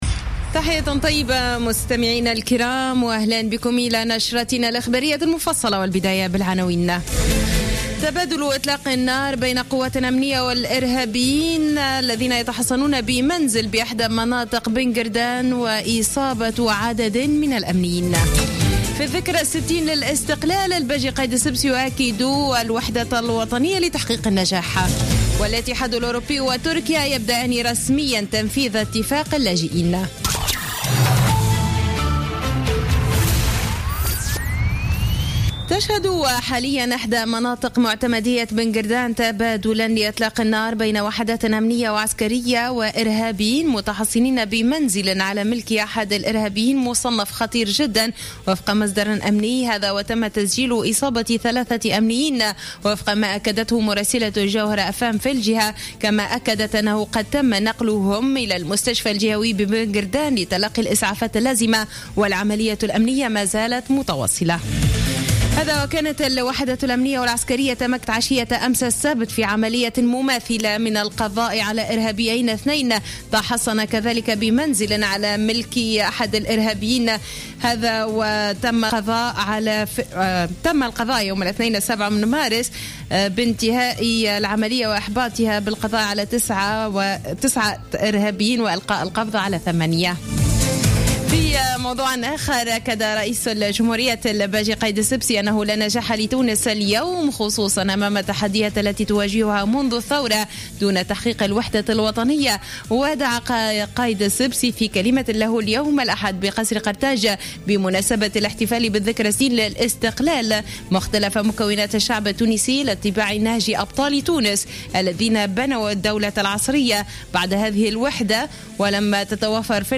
نشرة أخبار السابعة مساء ليوم الأحد 20 مارس 2016